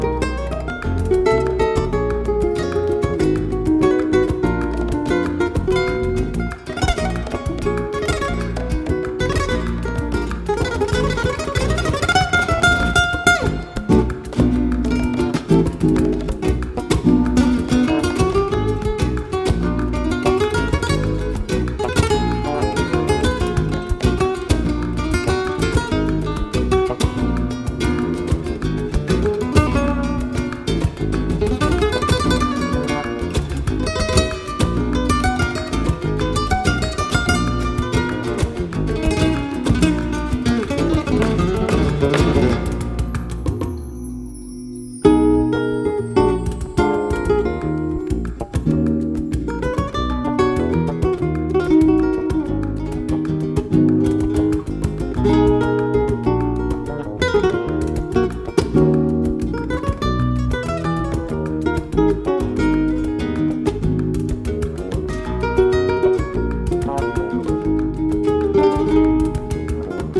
Zurück zu: Flamenco
Rumba